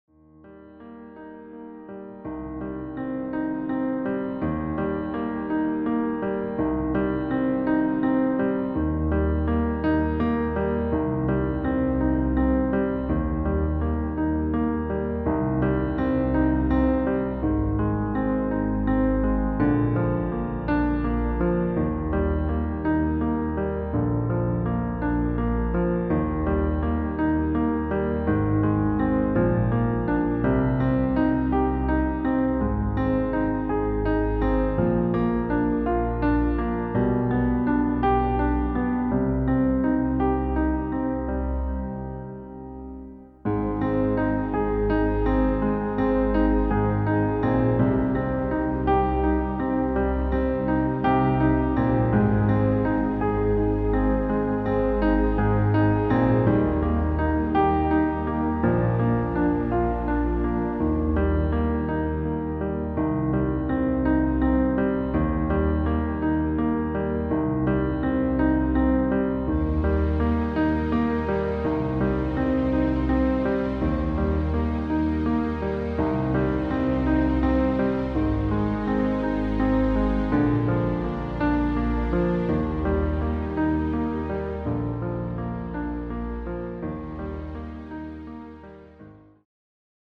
• Tonart: Eb Dur, E-Dur, F-Dur, G-Dur
• Art: Klavierversion mit Streicher
• Das Instrumental beinhaltet keine Leadstimme
Lediglich die Demos sind mit einem Fade-In/Out versehen.
Klavier / Streicher